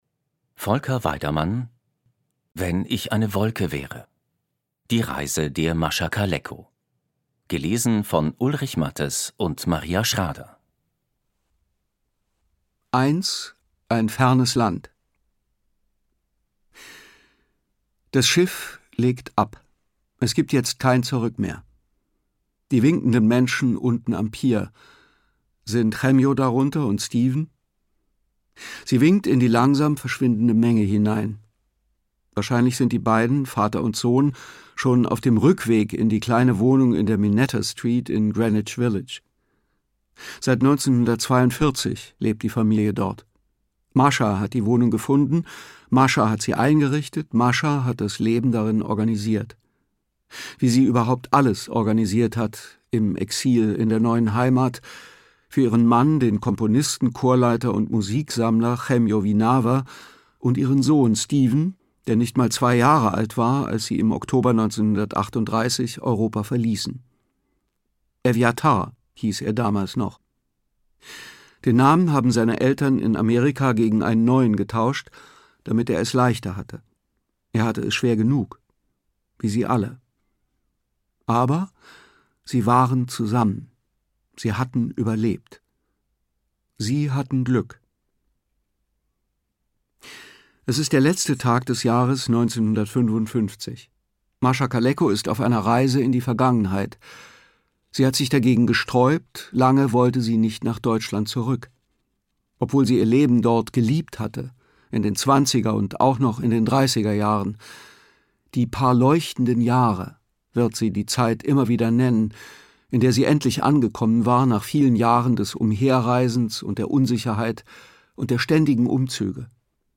Ungekürzte Lesung mit Ulrich Matthes und Maria Schrader (1 mp3-CD)
Ulrich Matthes, Maria Schrader (Sprecher)